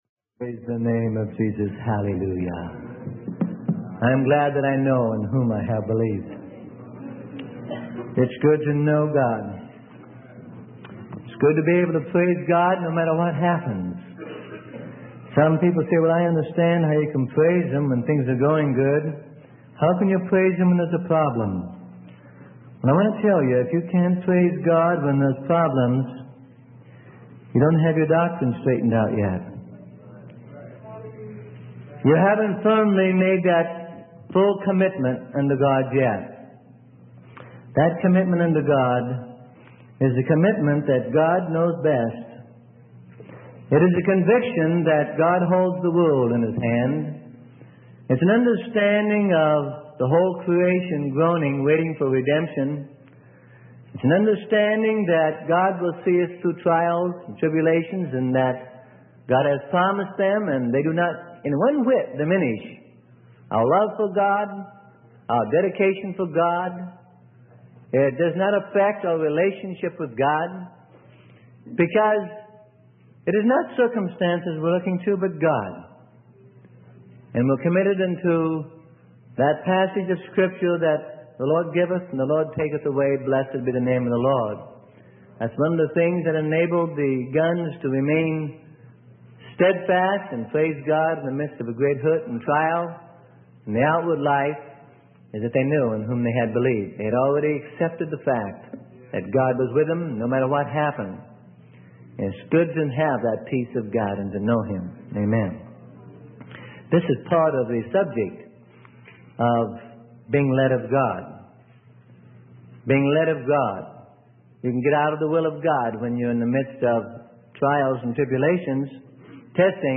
Sermon: Guidance from God - Part 14 - Freely Given Online Library